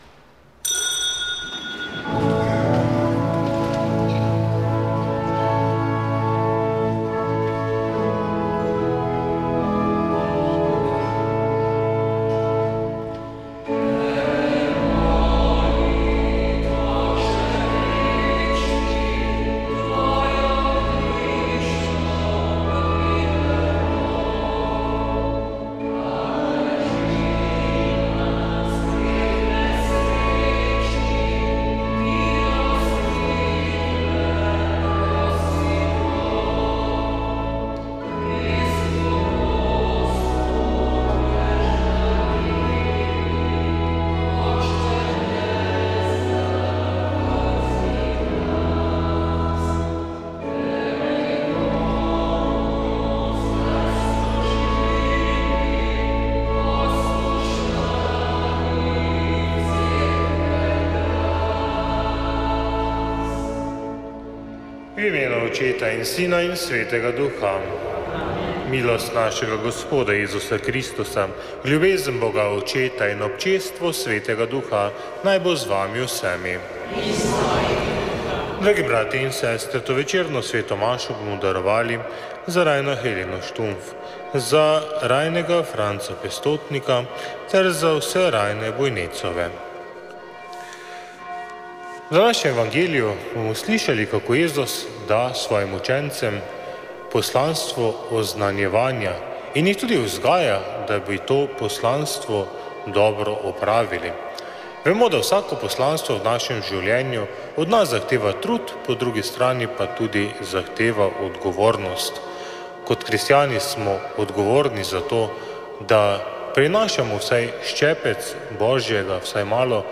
Sveta maša
Sv. maša iz cerkve Marijinega oznanjenja na Tromostovju v Ljubljani 18. 6.